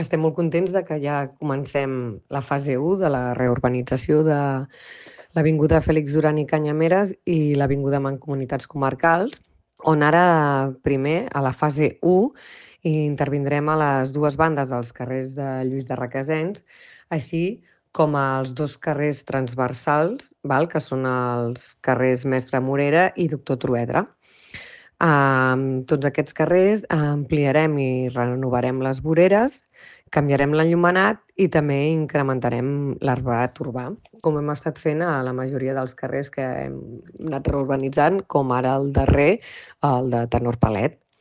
Elisabeth Plaza, regidora de Serveis Urbans i Via Pública